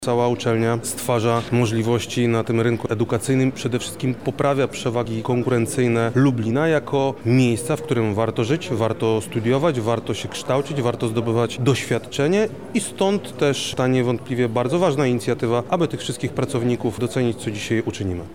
Wydarzenie odbyło się dzisiaj (29.11), o godzinie 12:00 w auli na Wydziale Prawa i Administracji UMCS.
– mówi Krzysztof Komorski, Wojewoda Lubelski.
wojewoda.mp3